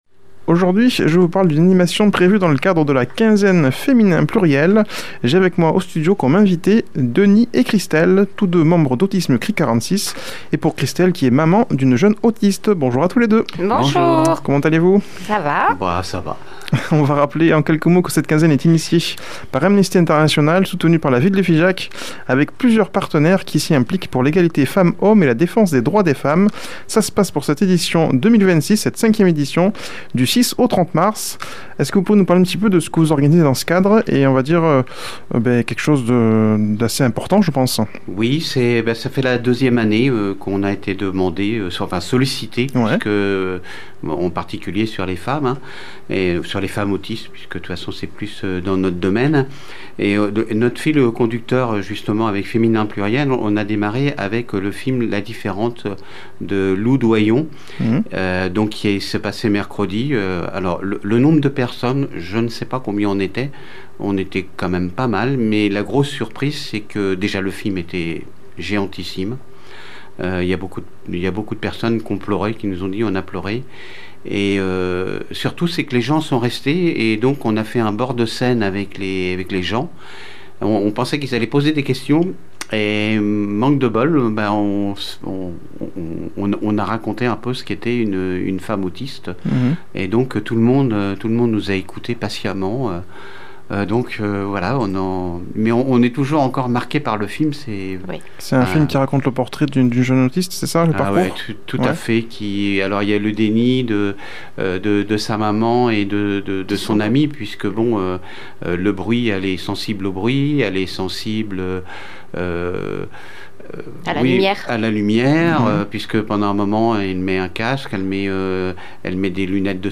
a comme invités au studio